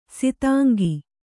♪ sitāngi